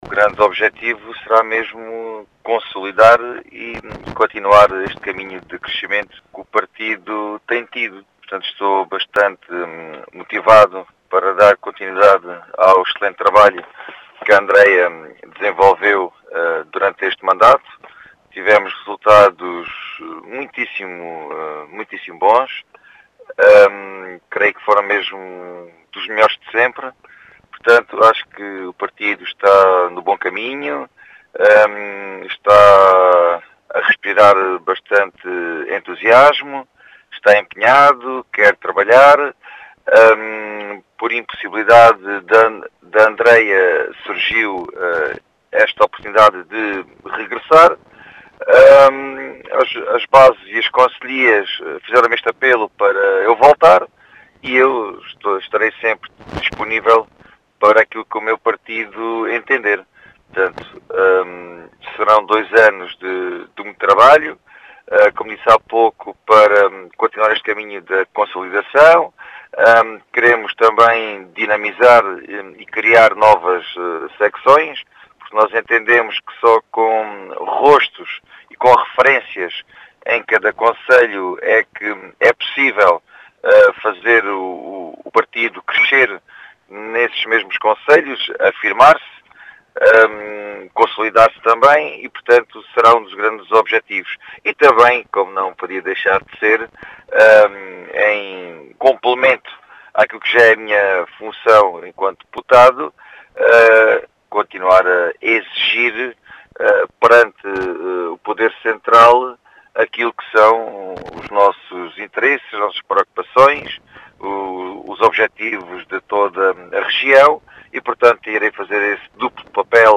As explicações foram deixadas à Rádio Vidigueira por Gonçalo Valente, o candidato à liderança da Comissão Politíca Distrital de Beja do PSD, que deixa os objetivos desta candidatura.